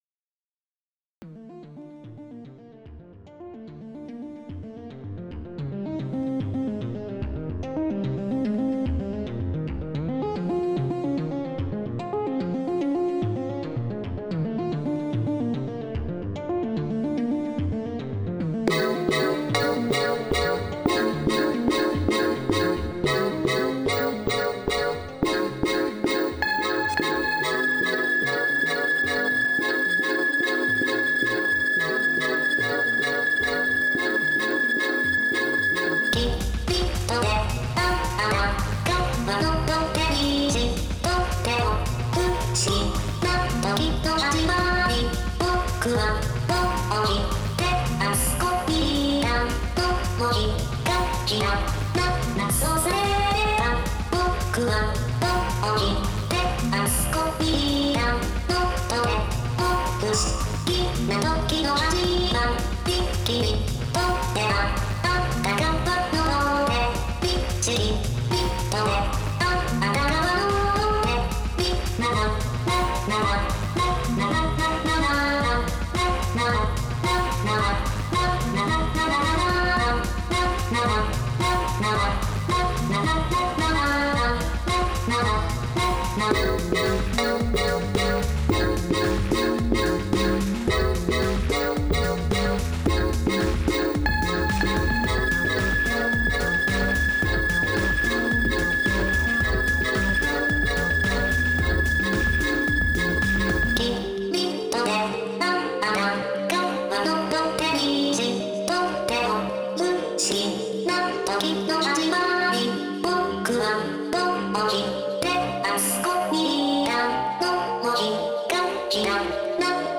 ・ボーカル有りの場合
・ボーカル無しの曲をベースに、Aques Tone 2をVocalトラックにセットし、MIDIクリップをブラウザーからドラッグ&ドロップ
・そのオーディオ・トラックにREAKTOR FXをセットし、THE MOUTHで加工したものをオーディオ・クリップに録音
Aques Tone 2で作ったボーカルをTHE MOUTHで加工すると、あまり音質が良くないように感じました。
音声合成で作ったボーカル音声は揺らぎが無く無機質なので、加工すると逆に粗が目立ってしまうのではないか？と思います。